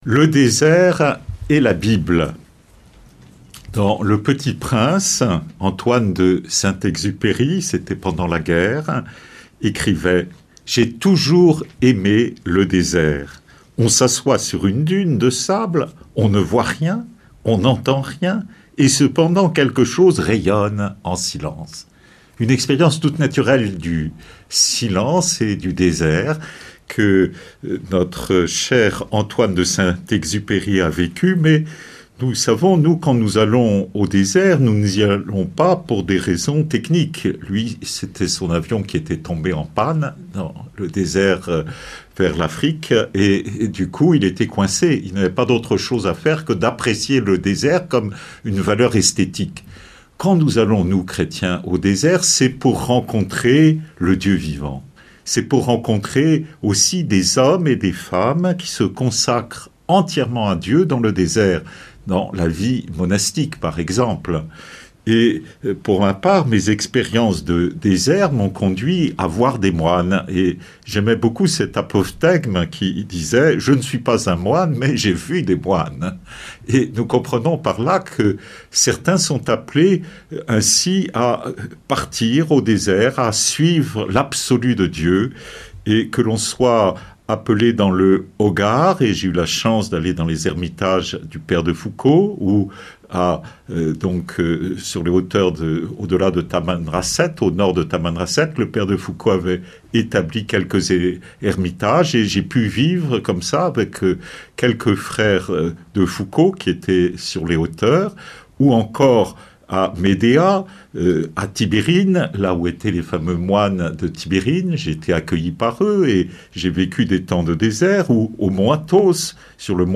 Conférence de carême des Dominicains de Toulouse